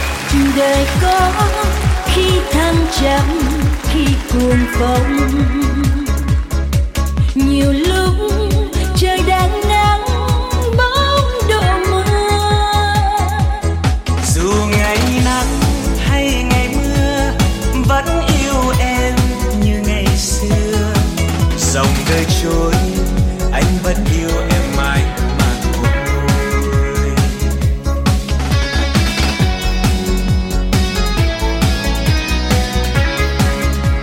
Nhạc Bolero.